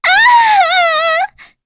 voices / heroes / en
Cleo-Vox_Dead.wav